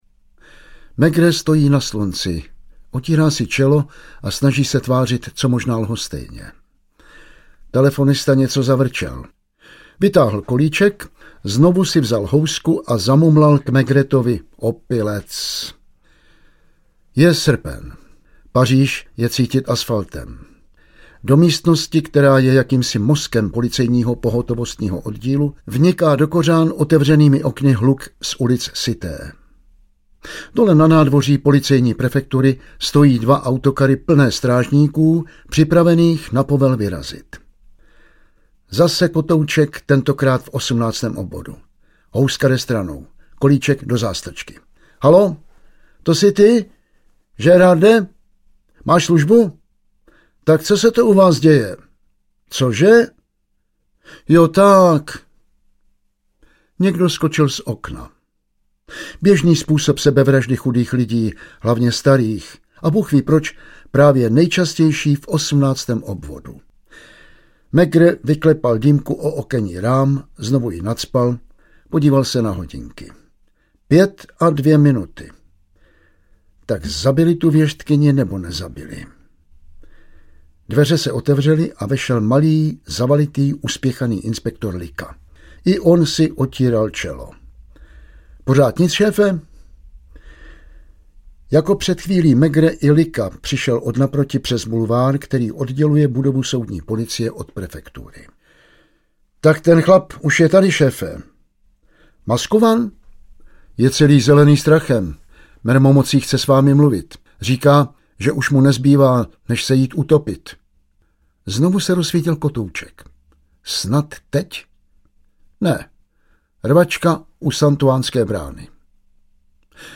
Audiobook
Read: Jan Vlasák